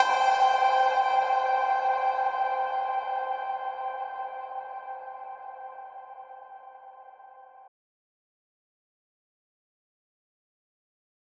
Percussion #18.wav